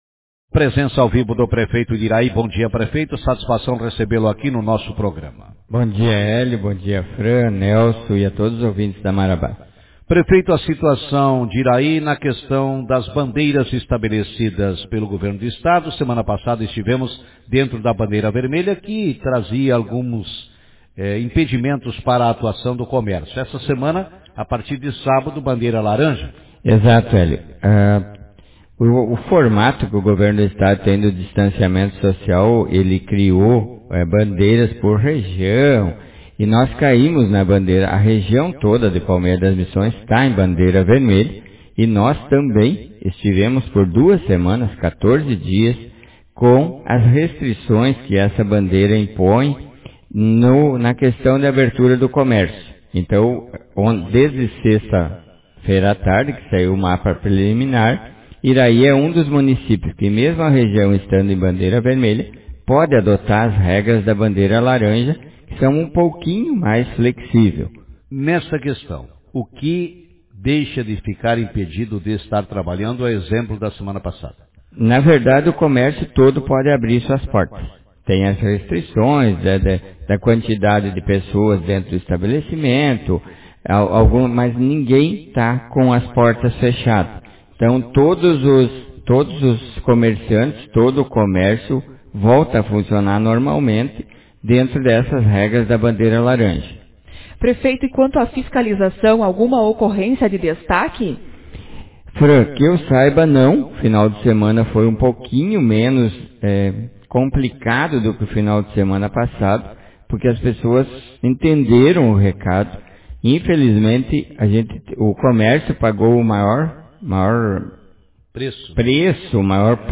Prefeito avalia classificação para bandeira laranja em Iraí Autor: Rádio Marabá 27/07/2020 0 Comentários Manchete Na manhã desta segunda-feira,27, o prefeito de Iraí, Antonio Vilson Bernardi, avaliou durante entrevista concedida no programa Café com Notícias, o retorno do município para a bandeira laranja, na classificação estabelecida pelo governo do Estado na avaliação da propagação do novo coronavírus em cada município gaúcho.